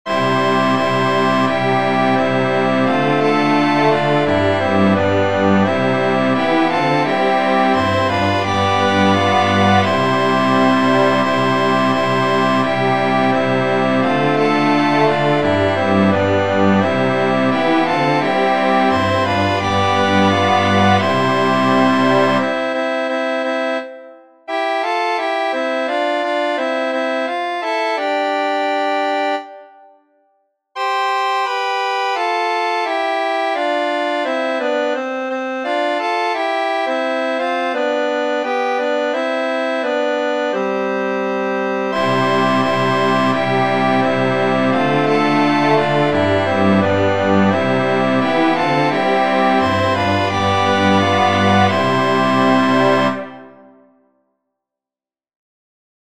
• Catégorie : Chants d’Acclamations.